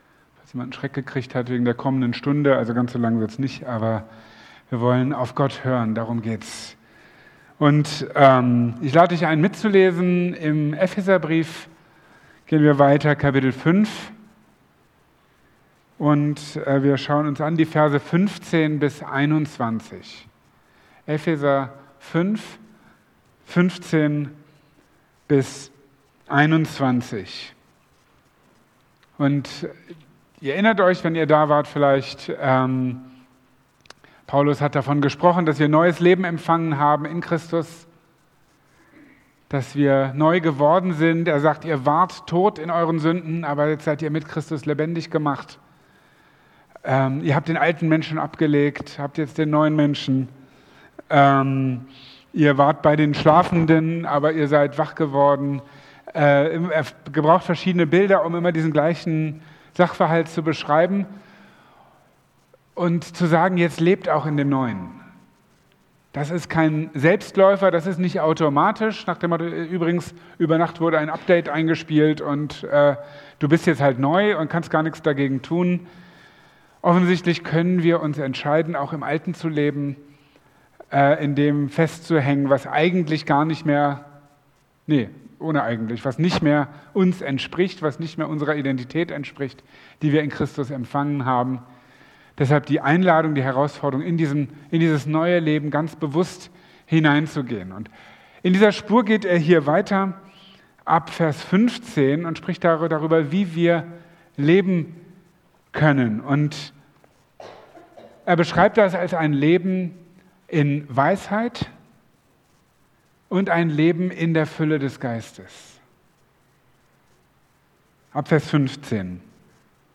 Marburger Predigten